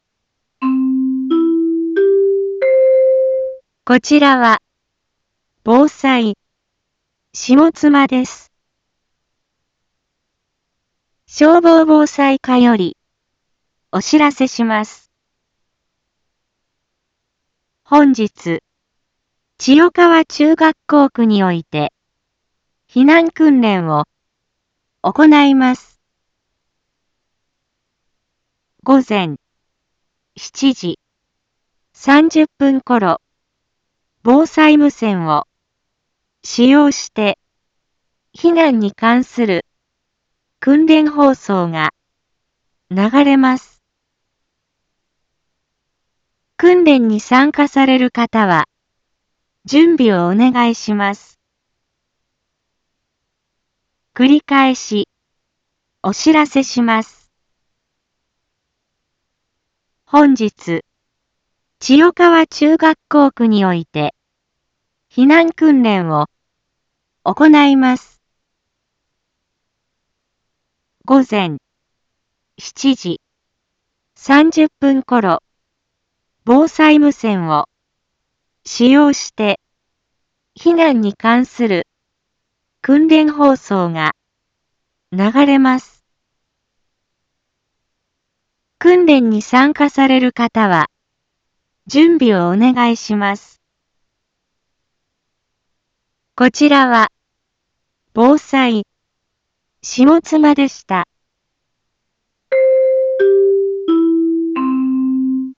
一般放送情報
Back Home 一般放送情報 音声放送 再生 一般放送情報 登録日時：2023-11-26 07:26:47 タイトル：【事前放送】避難訓練実施について インフォメーション：こちらは、防災、下妻です。